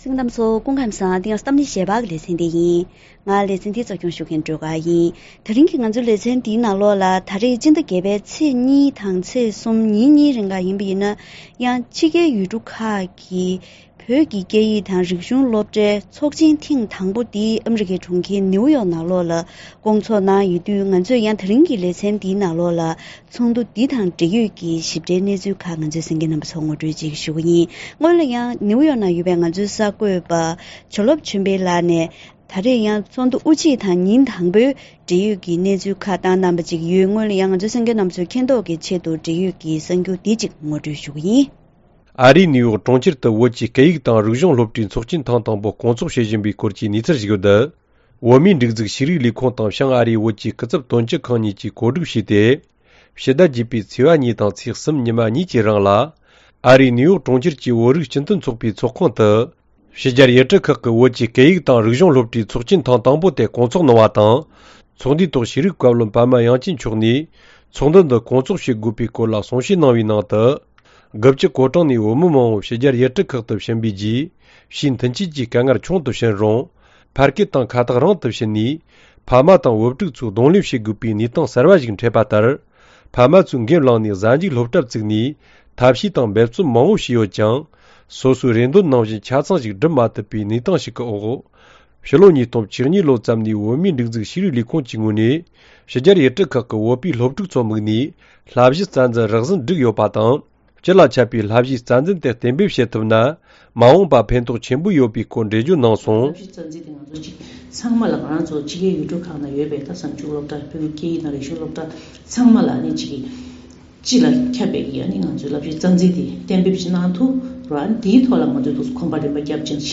ཐེངས་འདིའི་གཏམ་གླེང་ཞལ་པར་ལེ་ཚན་ནང་ཨ་རིའི་གྲོང་ཁྱེར་ནིའུ་ཡོག་ཏུ་སྐོང་ཚོགས་གནང་པའི་ཕྱི་རྒྱལ་ཡུལ་གྲུ་ཁག་གི་བོད་སྐད་ཡིག་དང་རིག་གཞུང་སློབ་གྲྭའི་ཚོགས་ཆེན་ཐེངས་དང་པོའི་ཐོག་བསླབ་གཞིའི་རྩ་འཛིན་གཏན་འབེབས་གནང་ཡོད་པས། ས་གནས་ཁག་གི་ཚོགས་ཞུགས་མི་སྣ་དང་ལྷན་དུ་ཞིབ་ཕྲའི་གནས་ཚུལ་ཐོག་བཀའ་མོལ་ཞུས་པ་ཞིག་གསན་རོགས་གནང་།